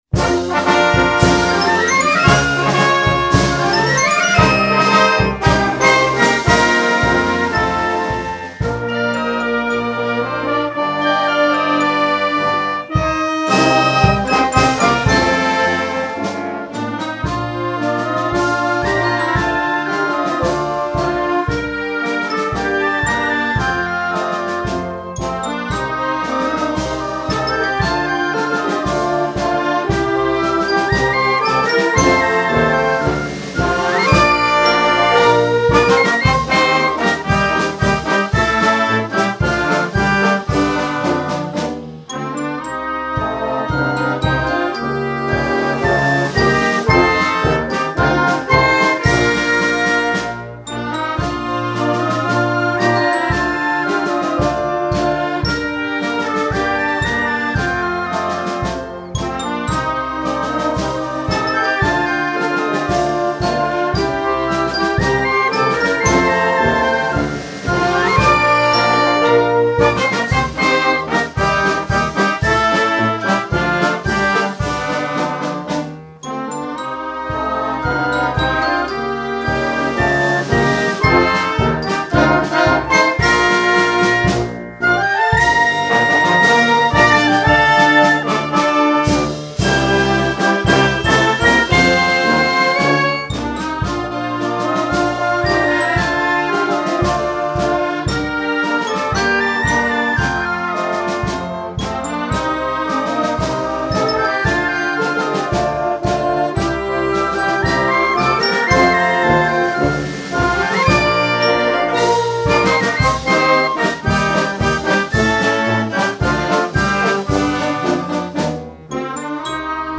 北海道名寄産業高等学校 校歌（伴奏）.mp3